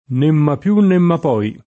n% mma pL2 nn% mma p0i], ma’ mai [